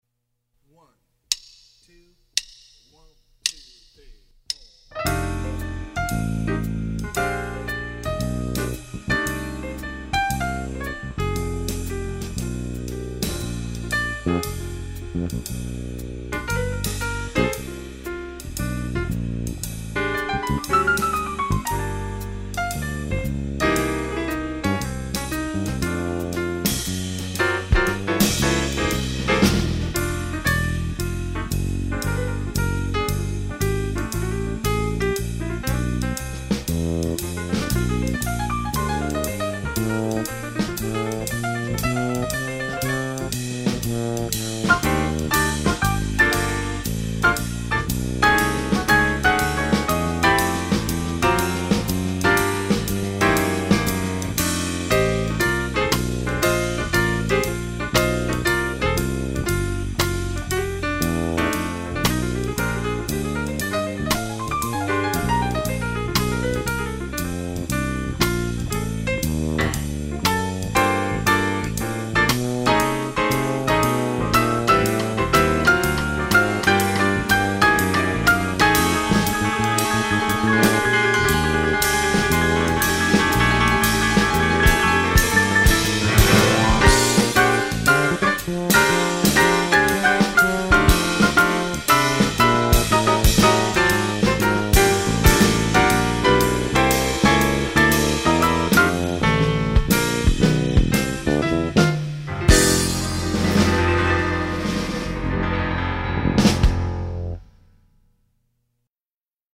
"Medium Swing"